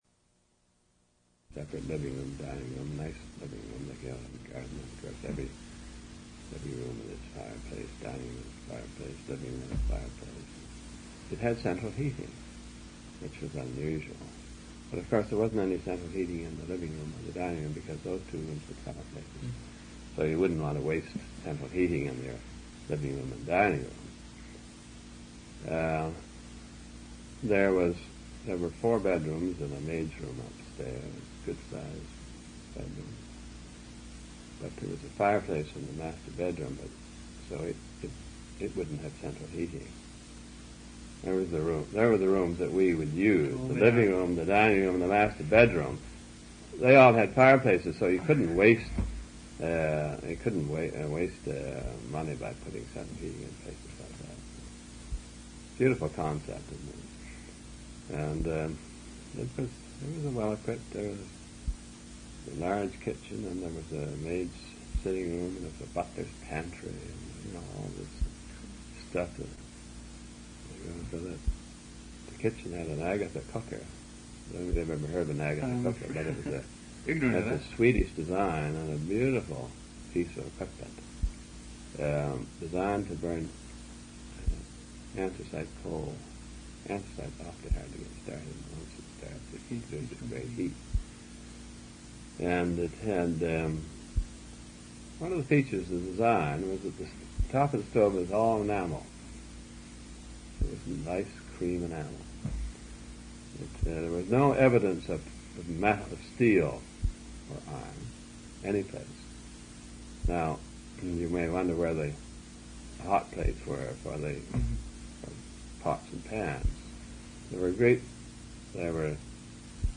Interviewee: Dunlap, Clarence R., b. 1908
An interview/narrative of Clarence R. Dunlap's experiences during World War II. Air Marshal Dunlap, C.B.E., served with the Royal Canadian Air Force.